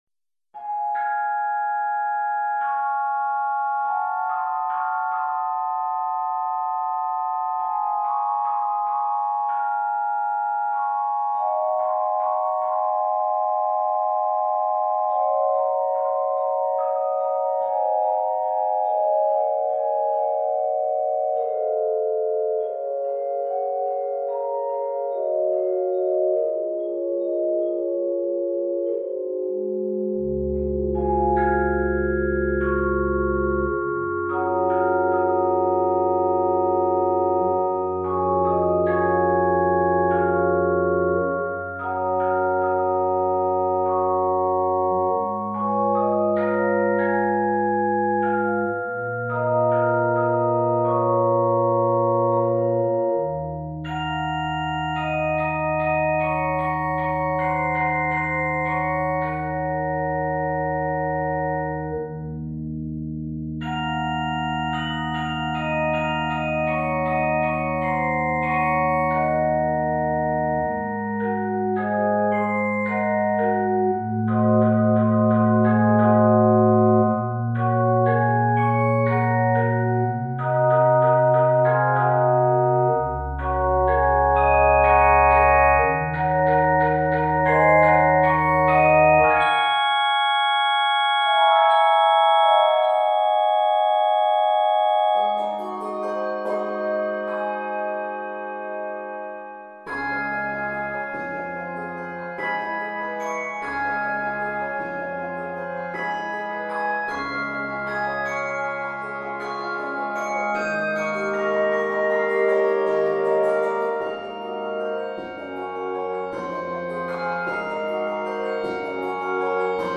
Keys: Db Major and c# minor Published by